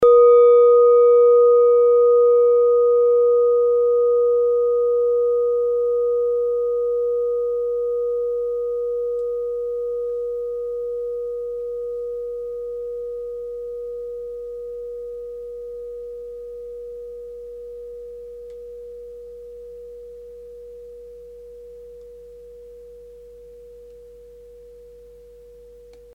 Klangschale Nepal Nr.16
Klangschale-Gewicht: 850g
Klangschale-Durchmesser: 13,2cm
(Ermittelt mit dem Filzklöppel oder Gummikernschlegel)
klangschale-nepal-16.mp3